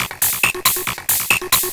DS 138-BPM A2.wav